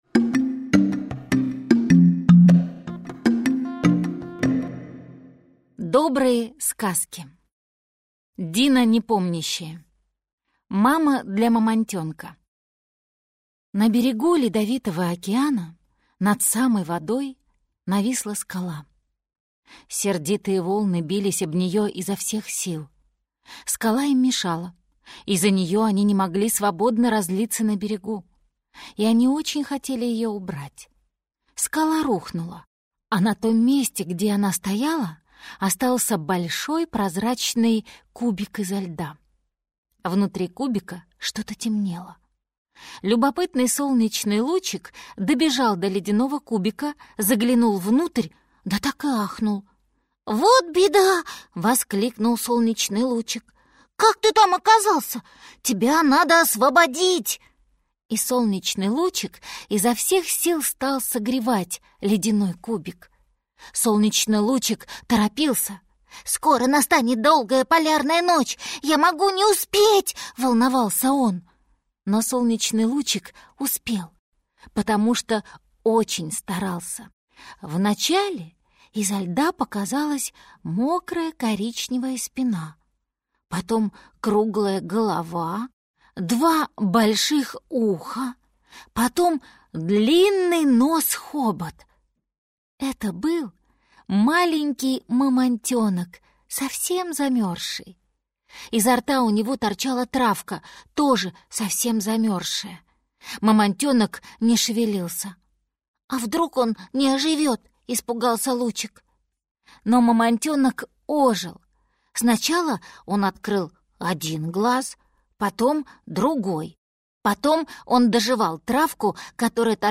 Аудиокнига Мама для мамонтёнка. Сказки | Библиотека аудиокниг